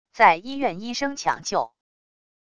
在医院医生抢救wav音频